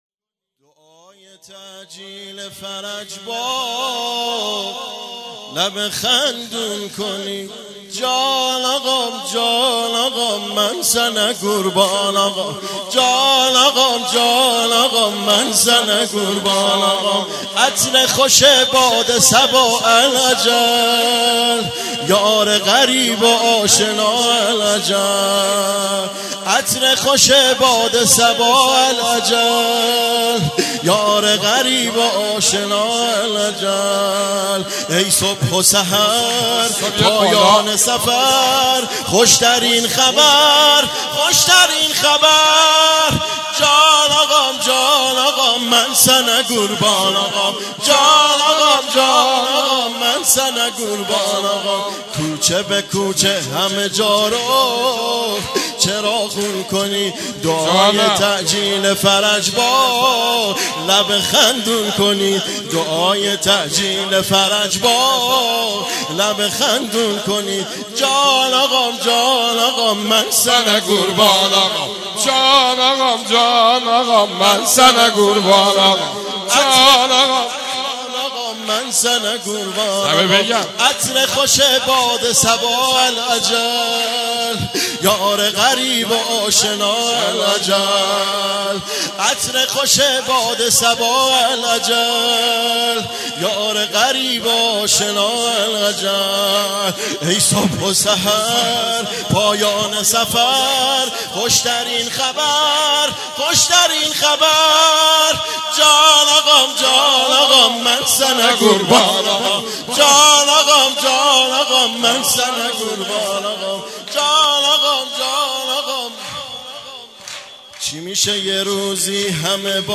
جشن میلاد امام زمان الزمان(عج)/هیئت محبان الرضا(ع)
سرود